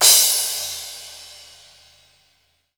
Index of /90_sSampleCDs/East Collexion - Drum 1 Dry/Partition C/VOLUME 002
CRASH031.wav